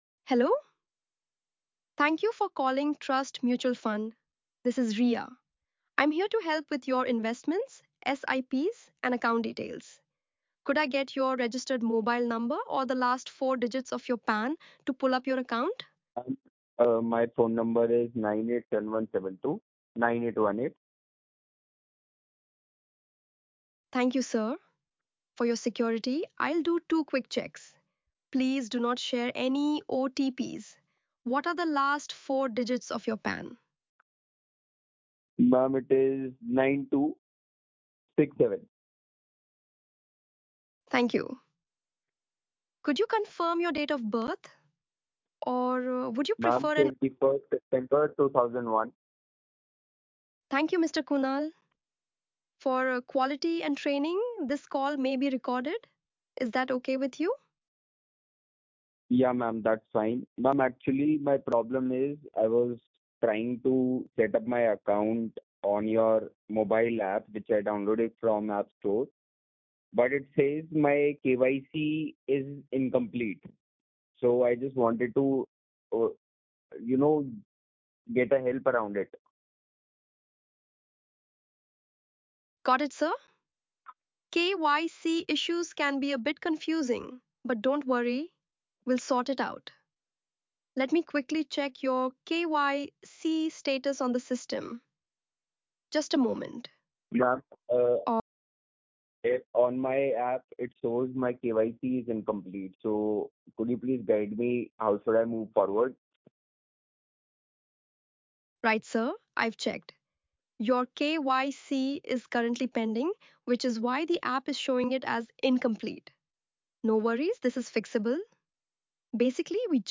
• English Indian
• Female